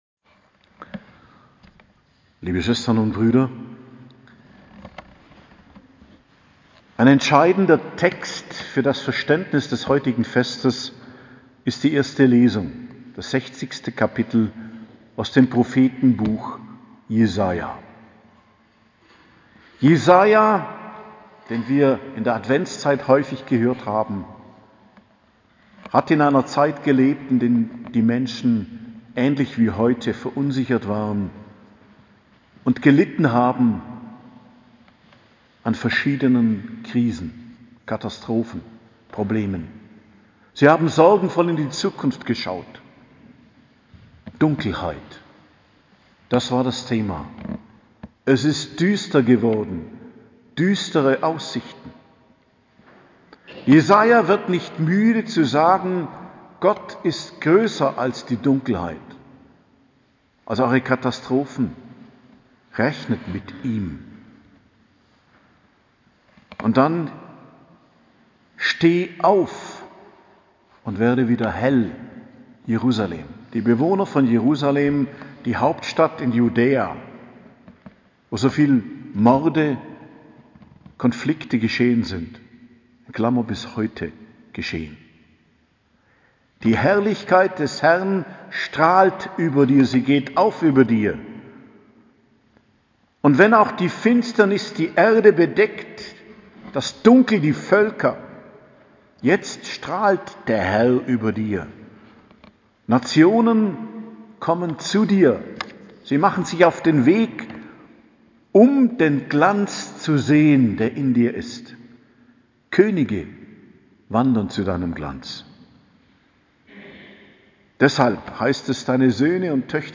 Predigt zum Hochfest Erscheinung des Herrn, 6.01.2022